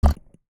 Item Button Mech Game.wav